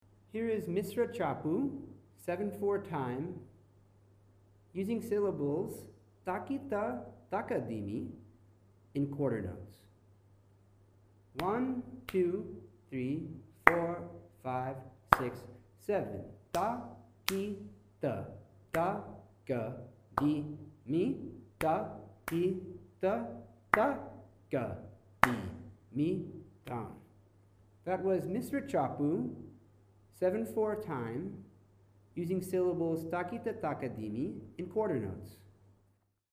Misra Chapu, known in Hindustani Music as Rupak, is shown by a series of claps and waves: wave on beat 1; wave on beat 2; clap on beat 4; clap on beat 6. It’s recited as Ta Ki Ta Ta Ka Di Mi, which is a 3 + 4 division of 7 beats.
Misra Chapu, 7/4 time, in Quarter Notes
misra-chapu-quarter-notes.mp3